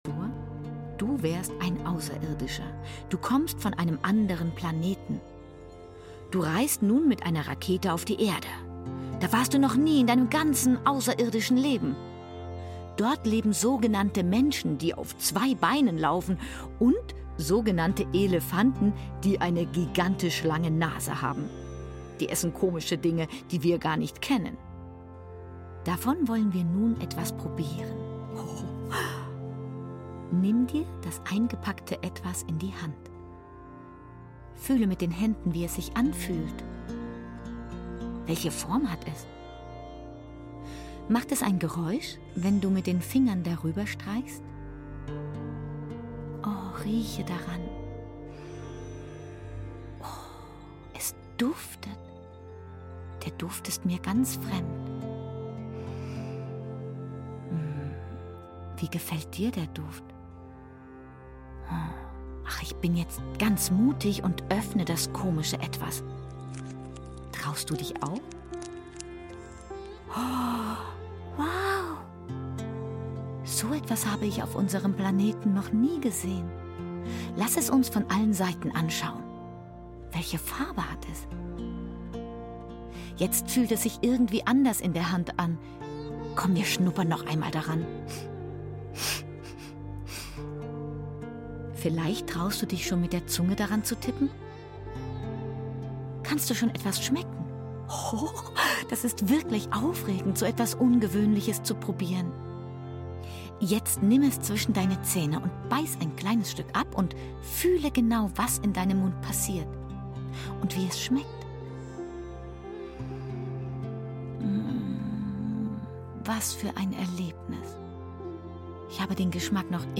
Hoerspiel-Ausschnitt2
Hoerspiel-Ausschnitt2.mp3